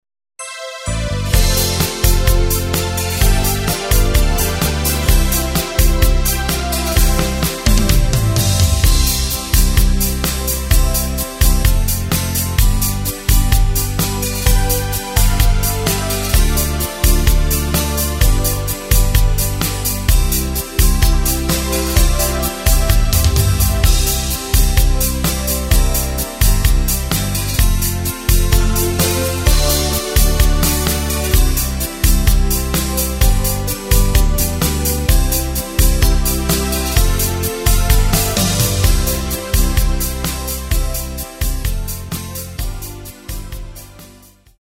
Takt:          4/4
Tempo:         128.00
Tonart:            C
Schöner Schlager!
Playback mp3 mit Lyrics